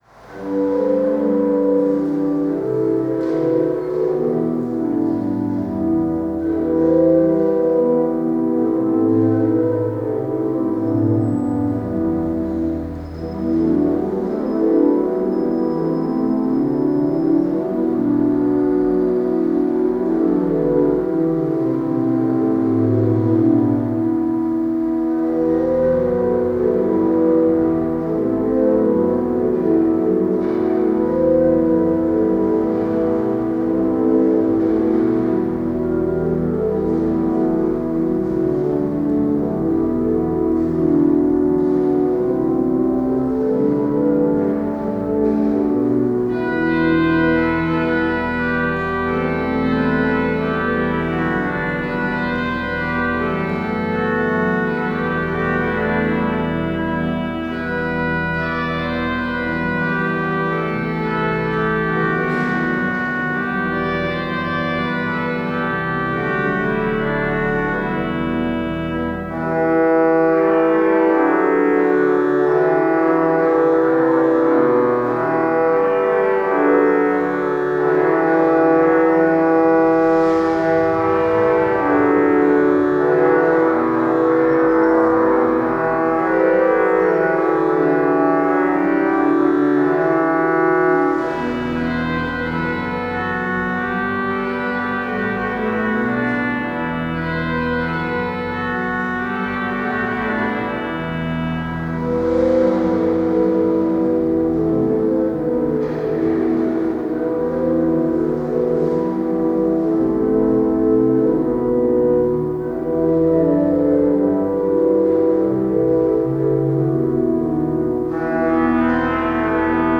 Improvisations sur des cantiques de Noël
Concert donné en l’église Saint-André de l’Europe (Paris 8),